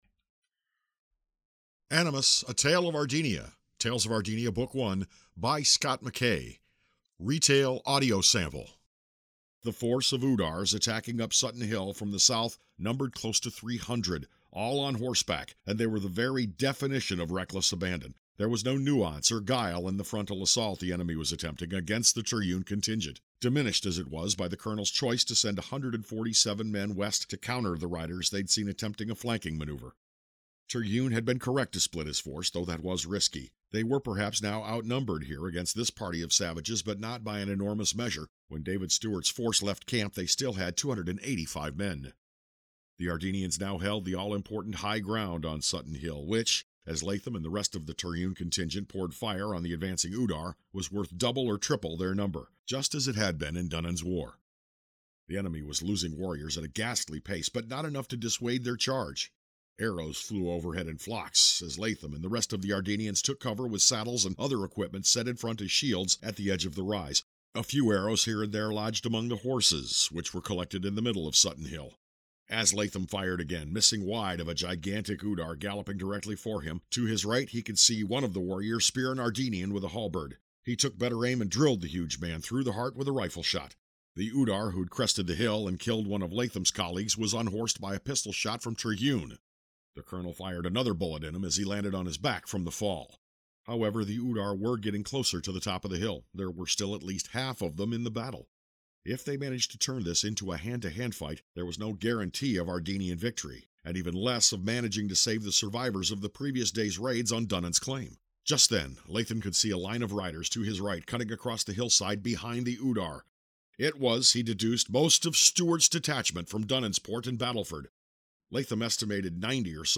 Here’s just a small sample of the audiobook, which will be downloadable at Audibel soon…
animus-retail-audio-sample.mp3